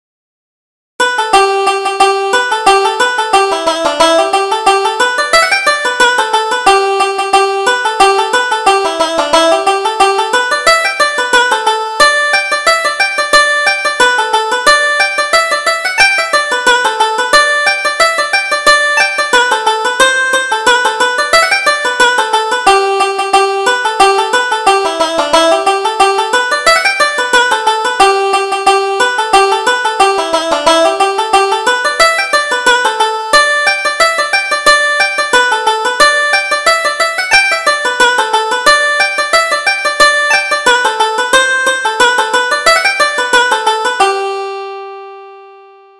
Reel: Peter Kennedy's Fancy